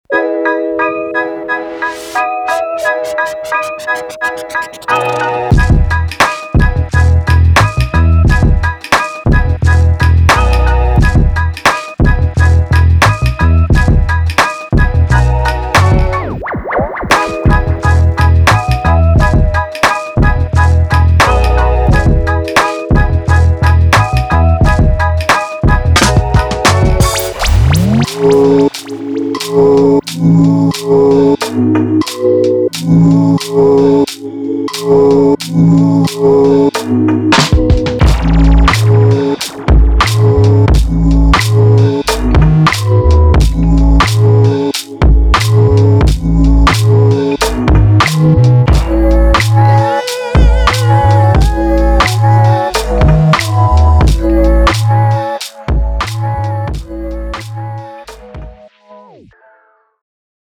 Soul
如果您正在寻找慢速的 Groove、泥泞的节拍、适合分层的氛围声音和悠闲的旋律，那么这个样本包就是您的理想选择。